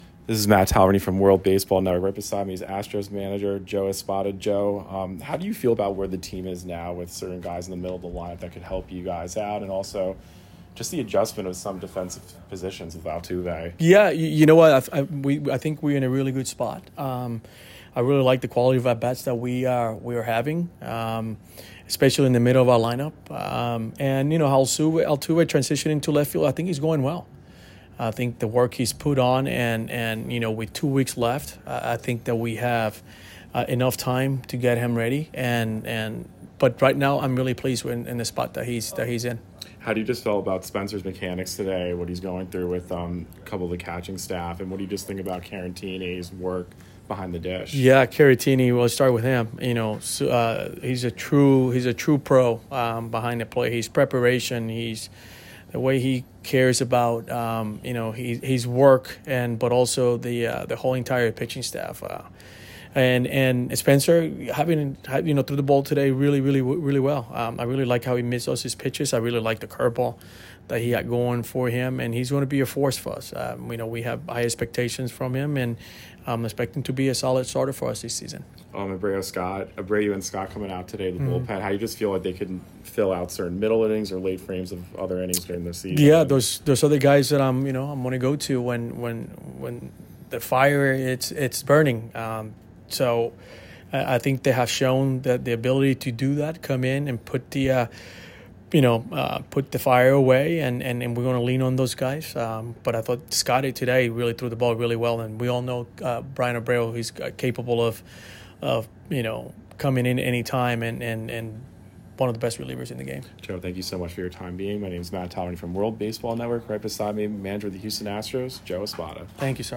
Houston Astros Spring Training Interview with Manager Joe Espada and International Player Outlook
WEST PALM BEACH, Fla. – World Baseball Network was on-site at CACTI Park of The Palm Beaches and caught up with Houston Astros manager Joe Espada ahead of his team’s spring training matchup against the St. Louis Cardinals. Espada discussed the Astros’ lineup depth, Altuve’s transition to left field, pitching evaluations, and key relievers expected to handle high-leverage situations.
Houston-Astros-Manager-Joe-Espada-Interview-with-World-Baseball-Network.mp3